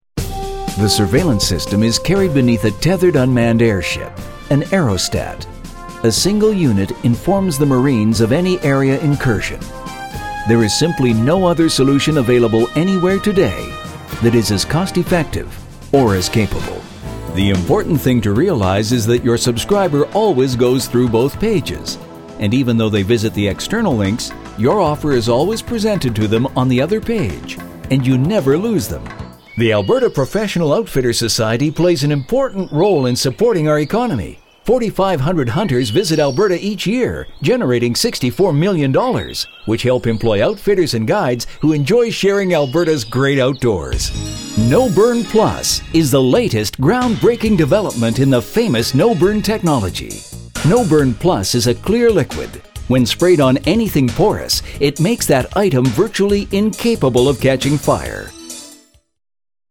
Voice over talent with audio production studio specializing in tv and radio commercial production, multi-media narration and character voices
Sprechprobe: Industrie (Muttersprache):